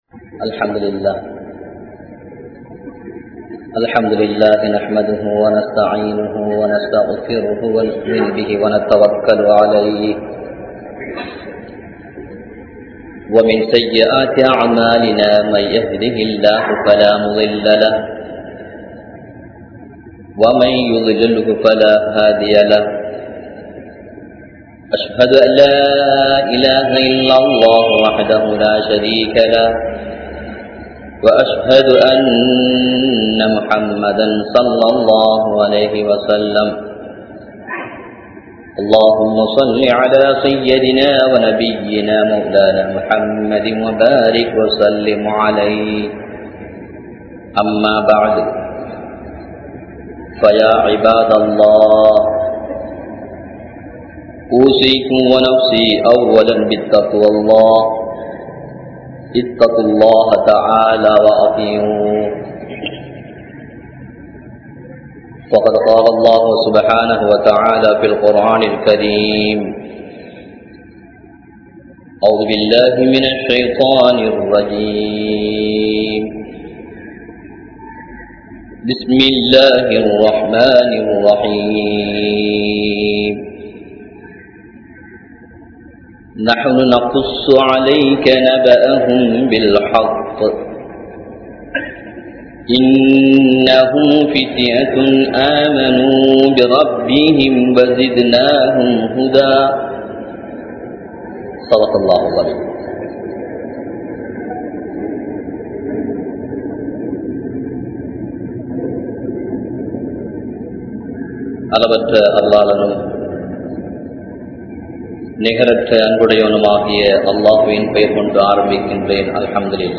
Islaathai Valarthavarhal vaalifarhal (இஸ்லாத்தை வளர்த்தவர்கள் வாலிபர்கள்) | Audio Bayans | All Ceylon Muslim Youth Community | Addalaichenai
Negombo, Grand Jumua Masjith